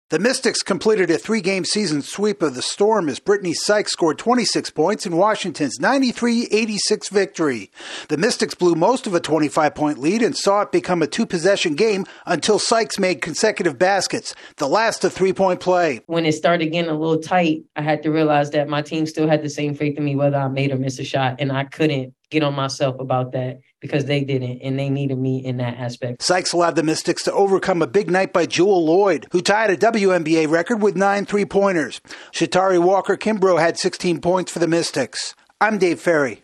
The Mystics pull out a win against the struggling Storm. AP correspondent